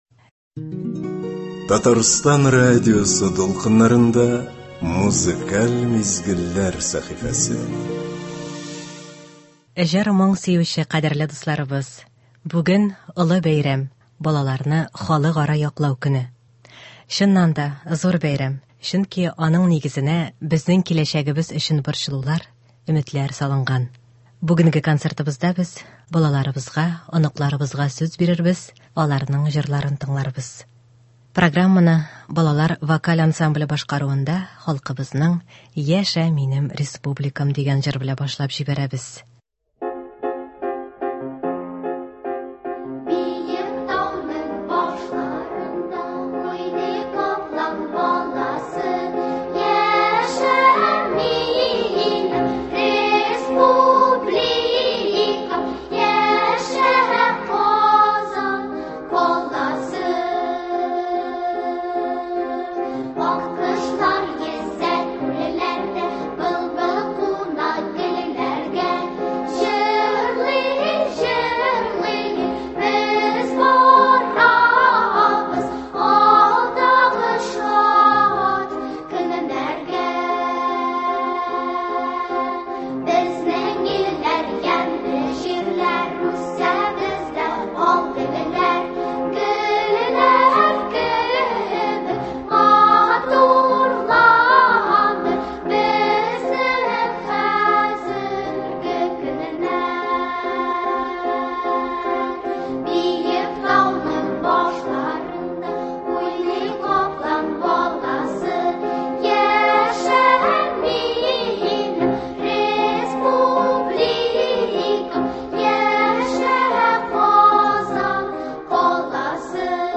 Бүгенге концертыбызда без балаларыбызга, оныкларыбызга сүз бирербез, аларның җырларын тыңларбыз.